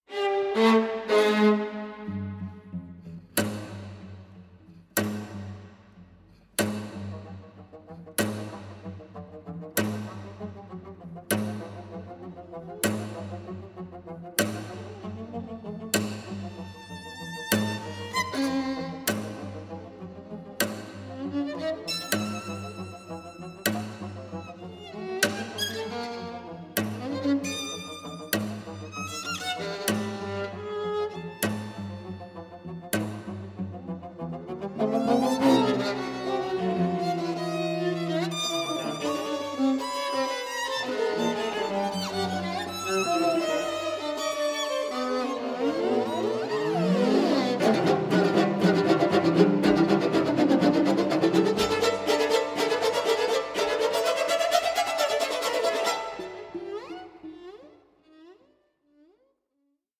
48/24 PCM Stereo  10,99 Select
AVANTGARDE CLASSICS FOR STRING QUARTET